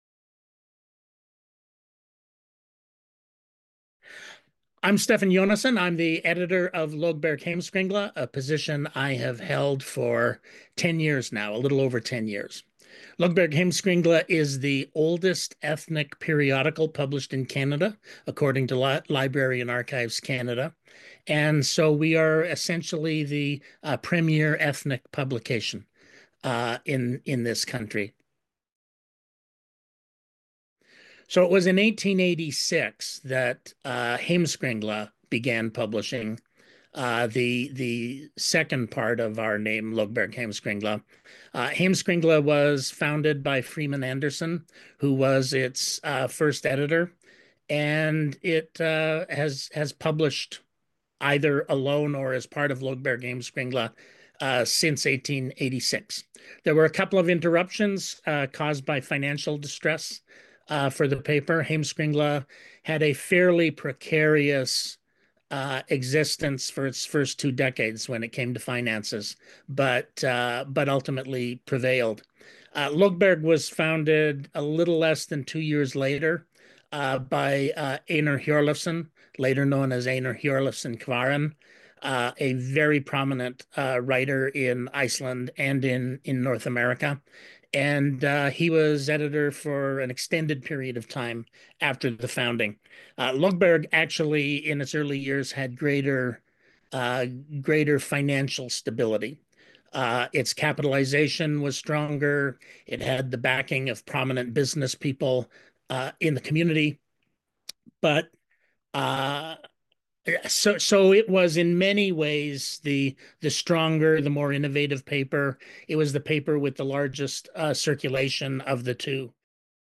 SJ-interview-clip-1.mp3